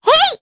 Luna-Vox_Attack1_jp.wav